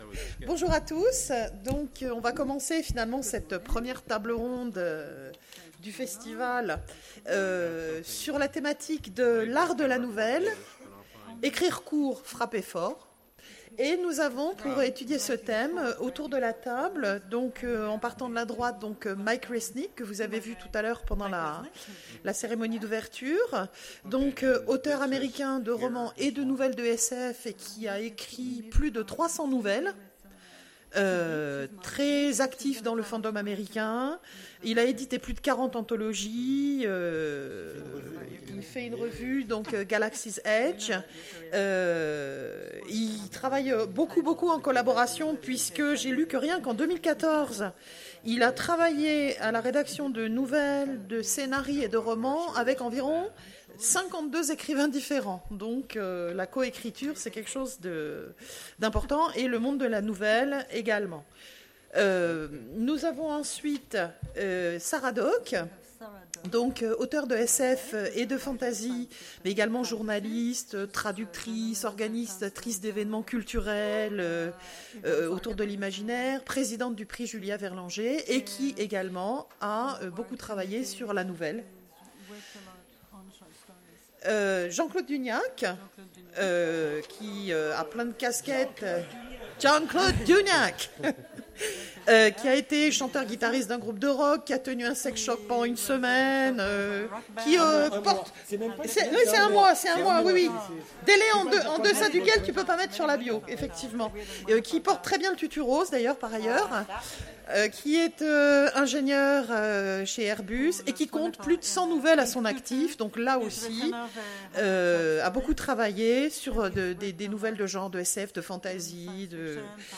Imaginales 2016 : Conférence L’art de la nouvelle